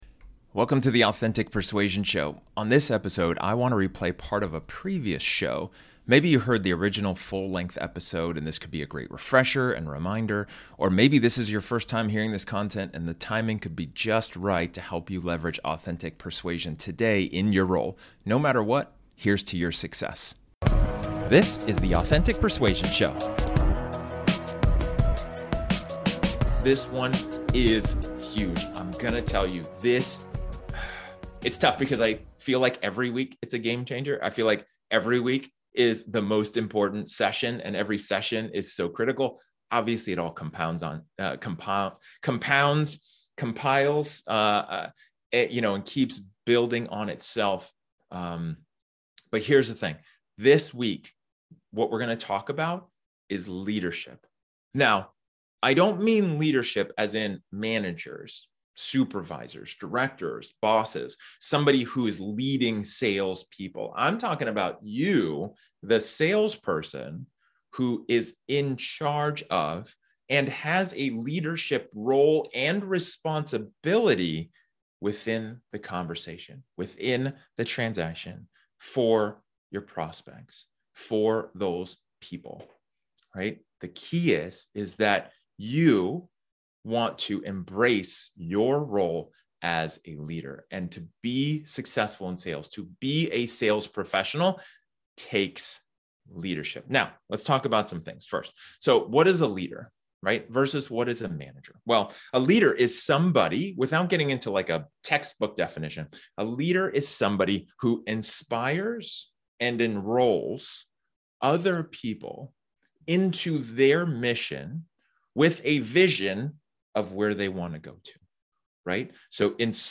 This episode is an excerpt from one of my training sessions where I talk about the question: "Are you being a Leader?"